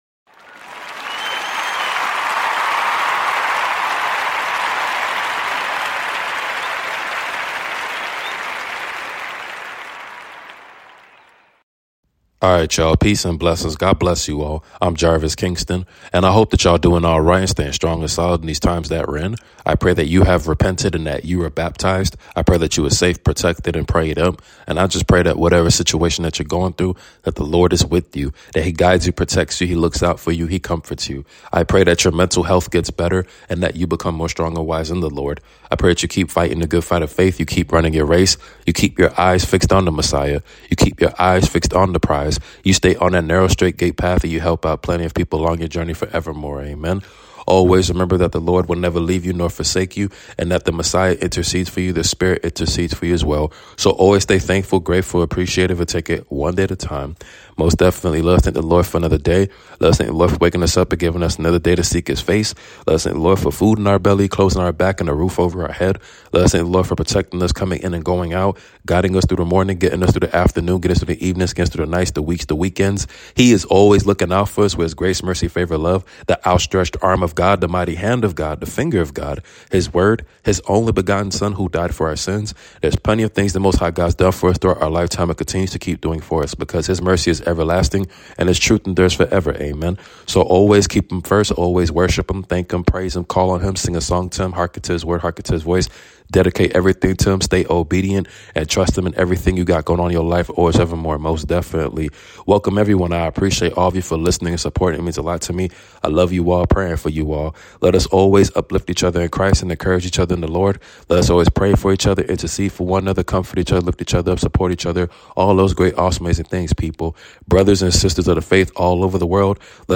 Your Nightly Prayer 🙏🏾 Proverbs 30:33